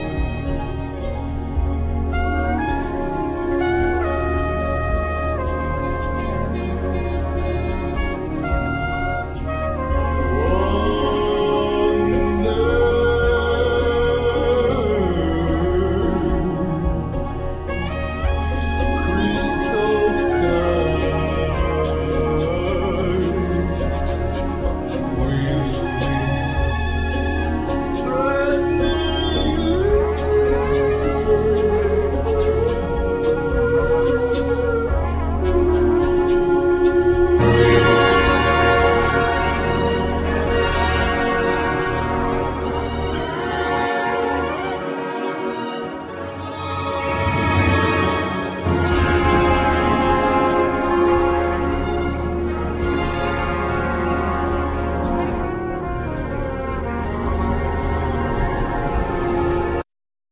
Machines,Clarinet
Machines,Vocals,Accordion
Sweet tribal drums
Trumpet
Electric bow,Slide guiter,Iron guitar
Electric guitar effects
Analog vintage synths